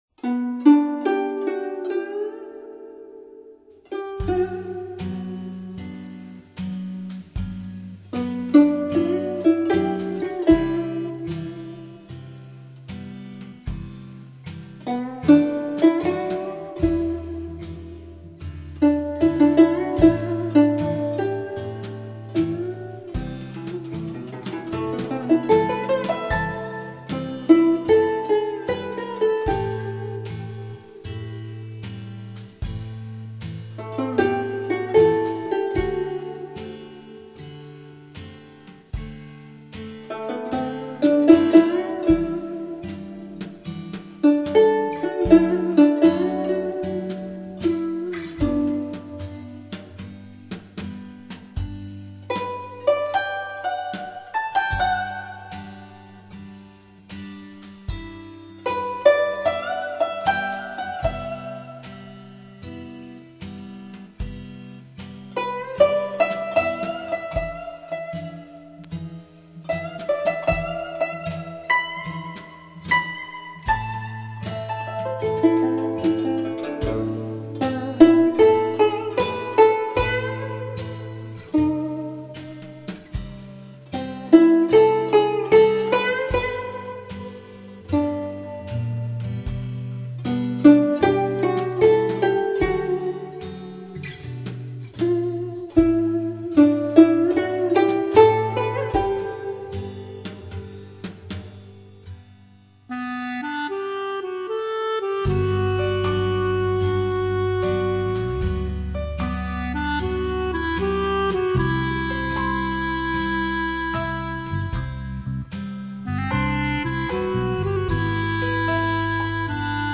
24Bit/192KHz Summing Mastering